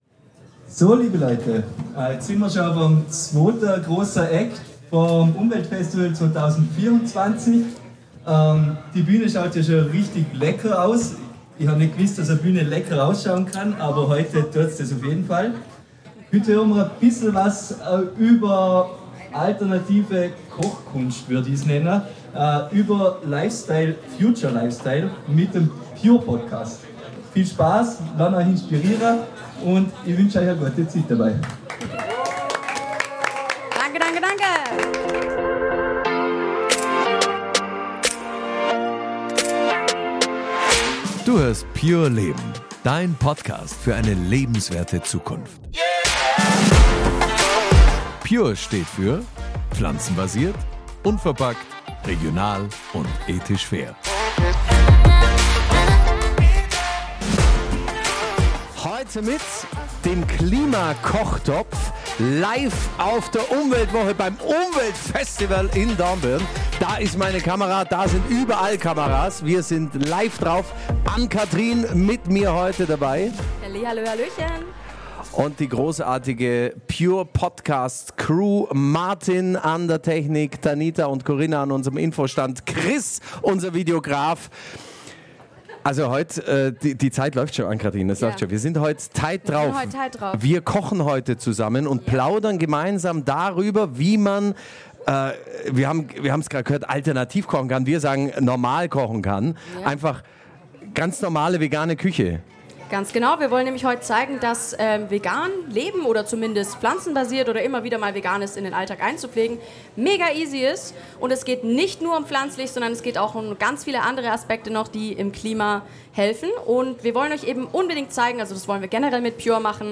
59. Klimakochtopf - Live bei der Umweltwoche ~ pure leben - Dein Future Lifestyle Podcast
Diesmal waren wir live beim umweltVestivals 2024 in Dornbirn – mit unserer Podcast-Show: Willkommen zum Klimakochtopf!